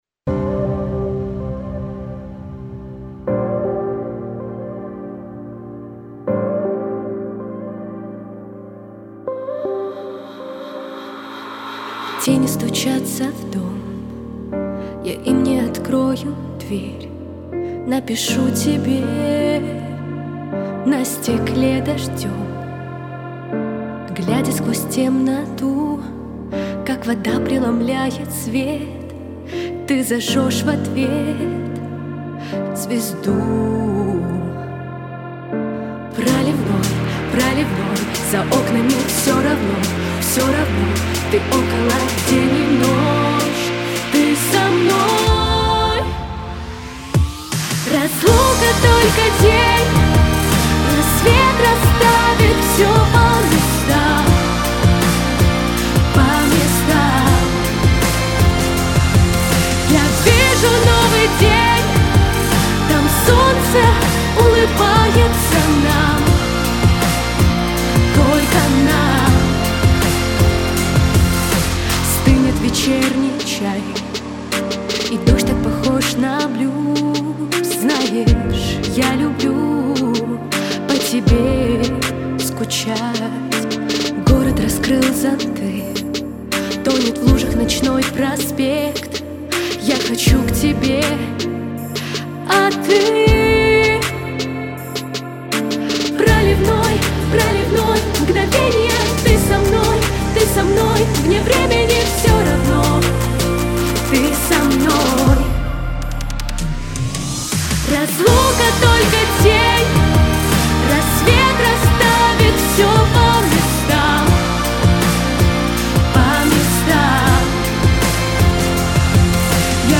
Мощная современная песня о любви.
Характер песни: позитивный, лирический.
Темп песни: медленный.
Диапазон: Соль малой октавы - Ми♭ второй октавы.